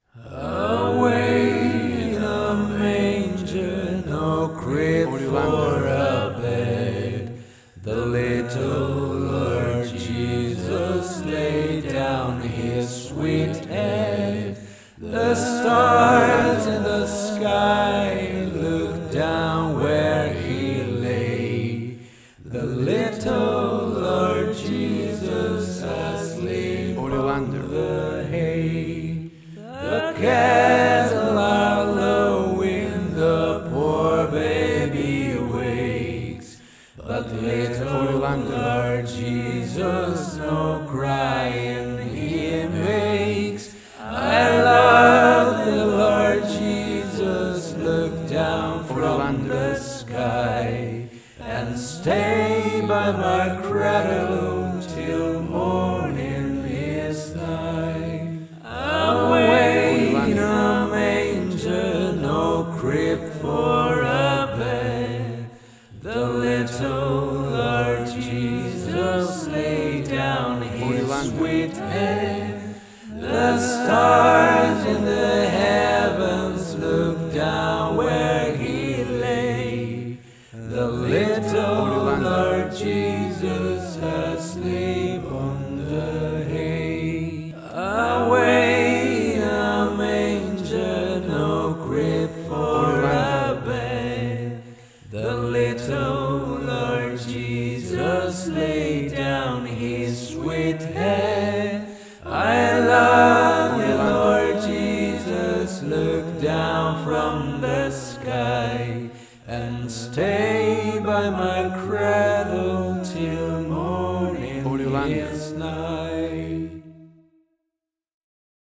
A beautiful vocal and voice only arrangement
Full of happy joyful festive sounds and holiday feeling!..
Tempo (BPM): 98